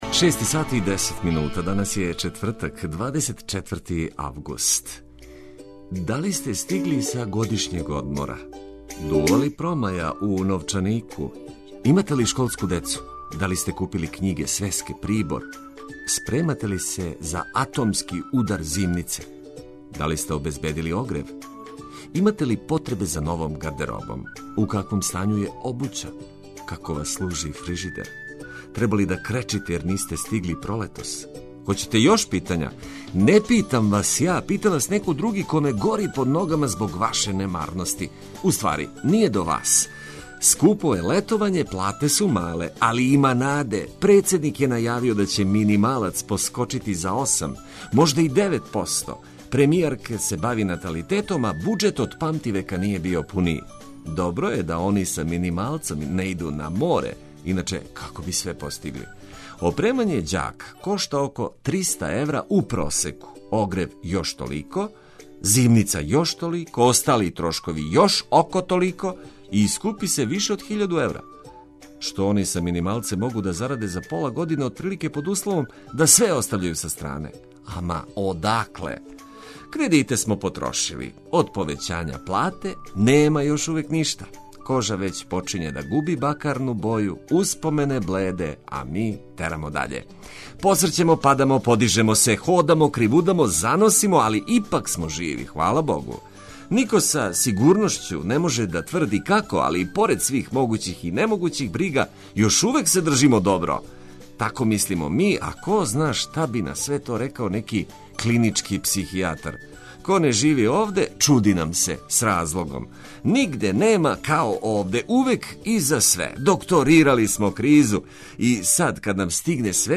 И овога јутра потрудићемо се да вас забавимо и информишемо уз музику која истерује из кревета и оне којима није баш до устајања.